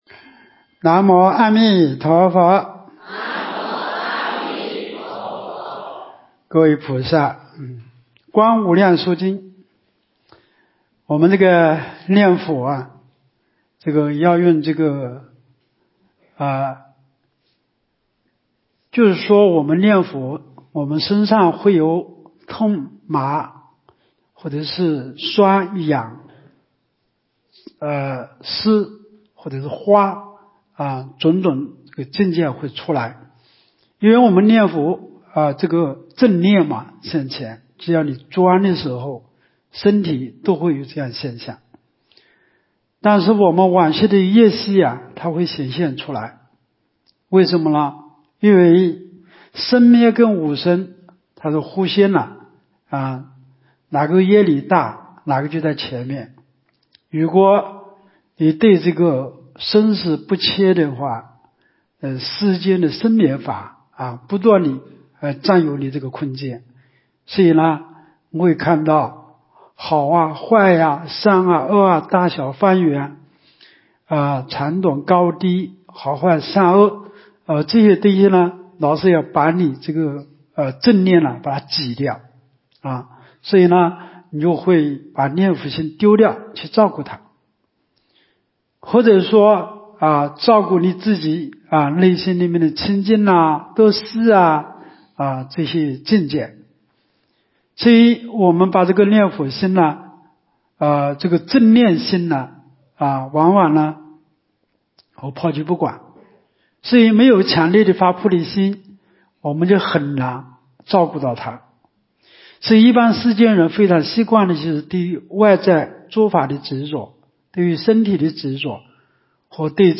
2024冬季佛七开示（十三）（观经）